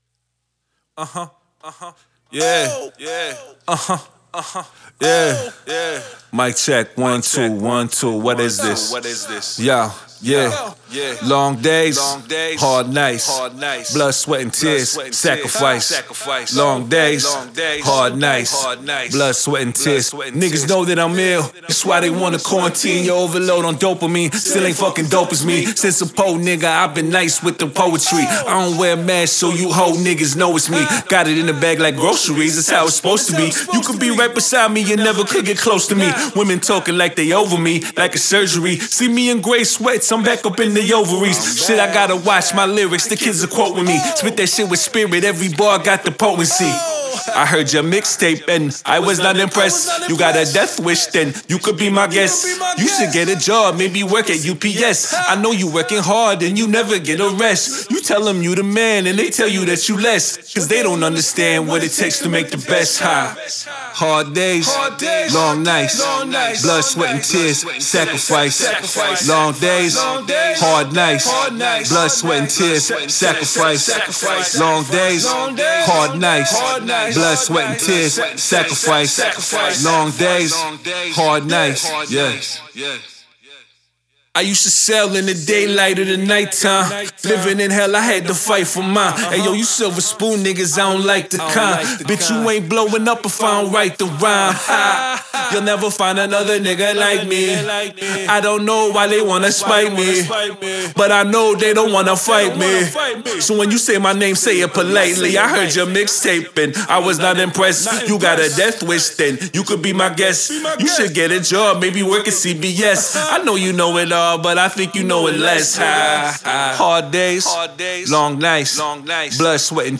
Description : Classic Canadian Hip Hop.